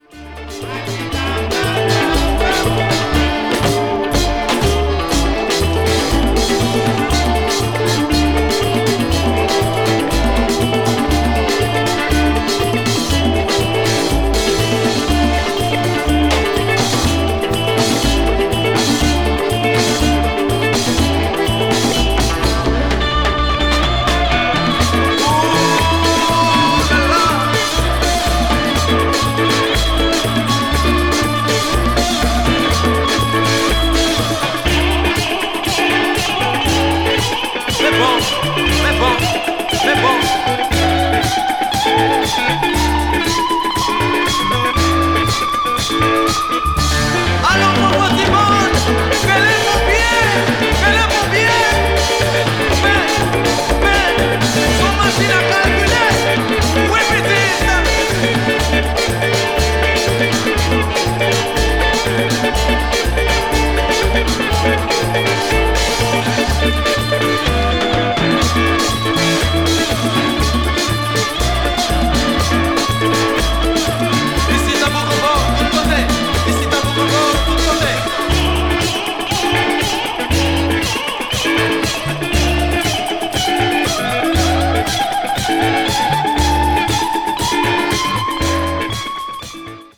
media : EX/EX(わずかにチリノイズが入る箇所あり)
carib   compas   haiti   world music